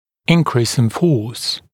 [‘ɪnkriːs ɪn fɔːs][‘инкри:с ин фо:с]увеличение силы